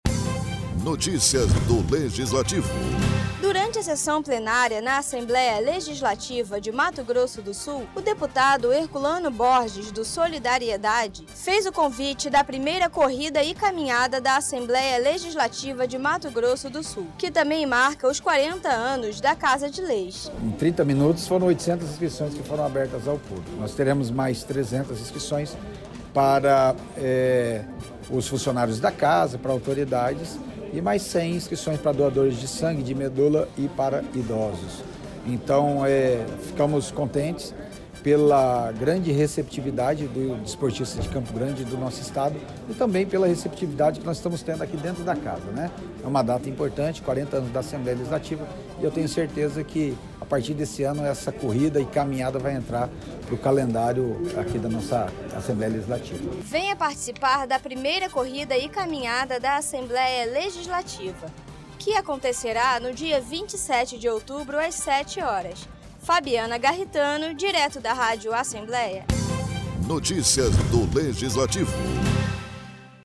O 2º secretário do Parlamento Estadual, deputado Herculano Borges, do Solidariedade usou a tribuna na sessão desta terça-feira, para informar a programação da 1ª Corrida e Caminhada da Assembleia Legislativa de Mato Grosso do Sul, que será realizada no dia 27 de outubro, a partir das 7h.